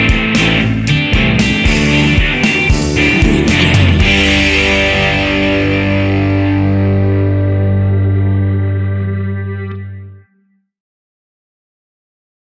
Ionian/Major
fun
energetic
uplifting
acoustic guitars
drums
bass guitar
electric guitar
piano
organ